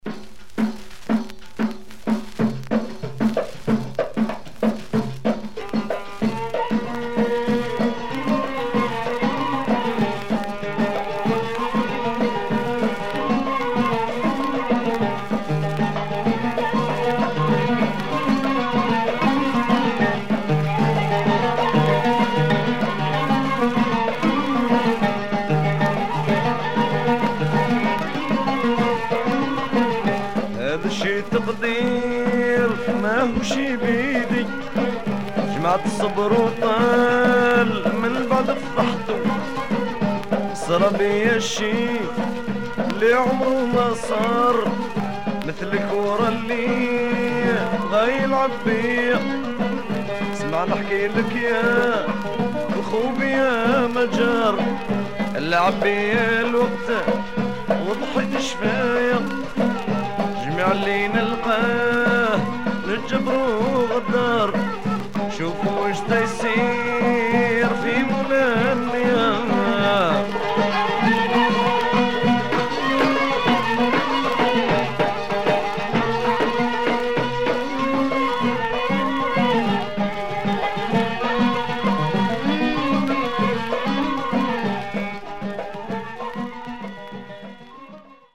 Raw, rare and beautiful proto rai 7' pressed in Algeria